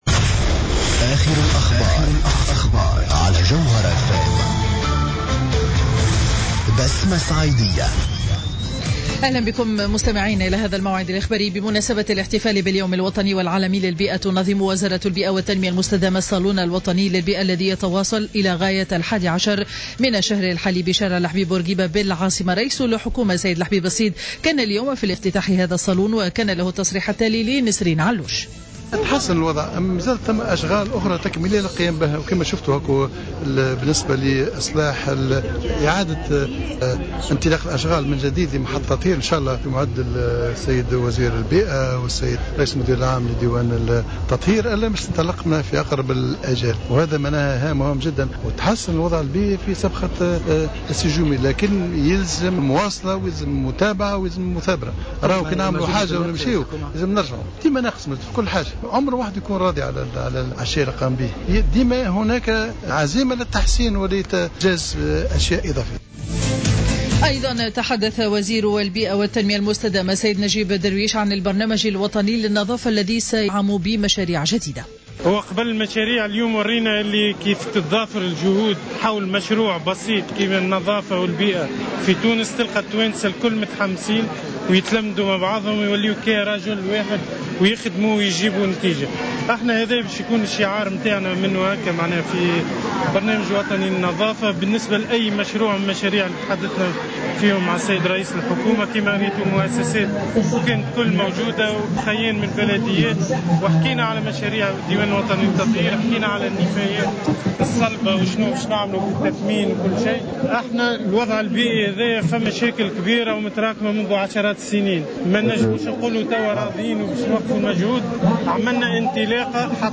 نشرة أخبار منتصف النهار ليوم الثلاثاء 09 جوان 2015